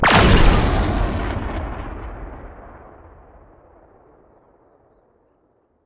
torpidoa.ogg